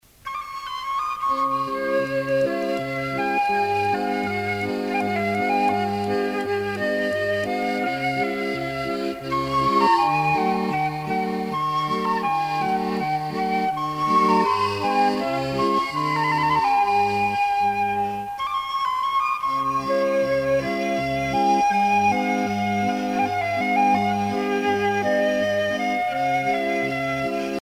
danse : menuet
Pièce musicale éditée